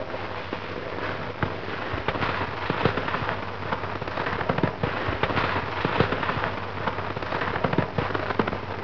carsnow.wav